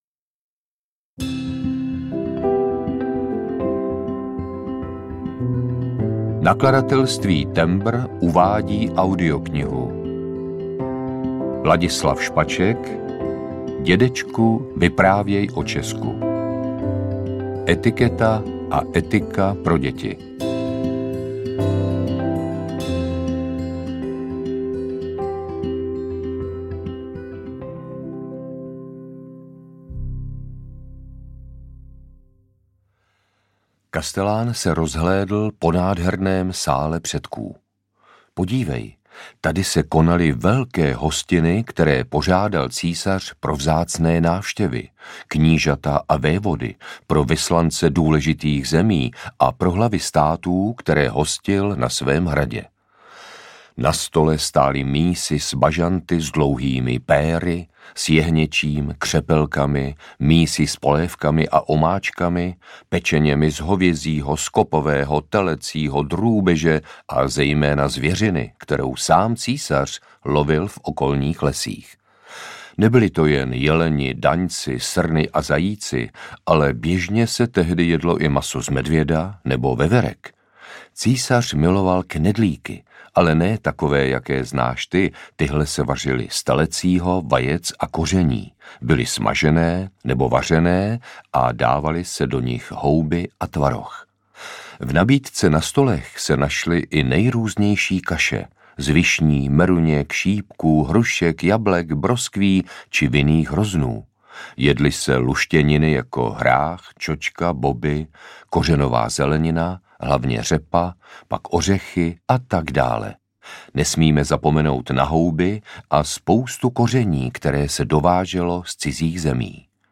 Dědečku, vyprávěj o Česku audiokniha
Ukázka z knihy
• InterpretLadislav Špaček